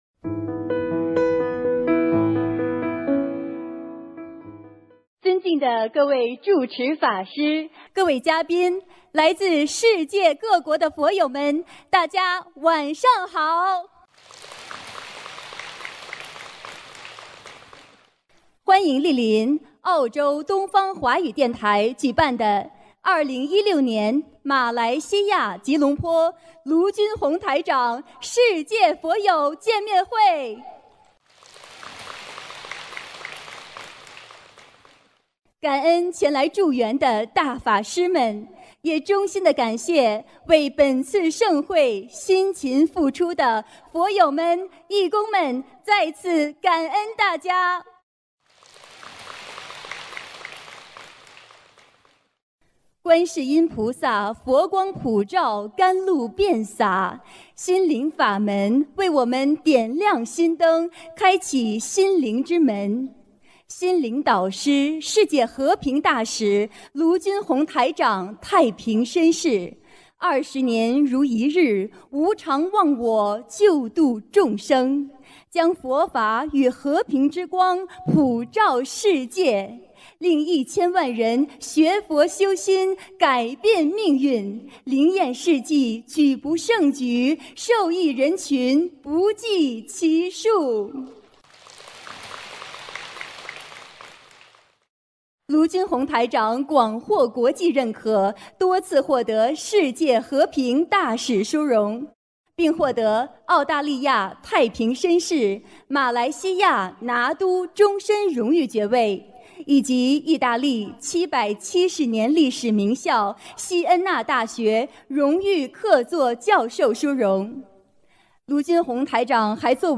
2016年12月24日马来西亚吉隆坡见面会开示（视音文图） - 2016年 - 心如菩提 - Powered by Discuz!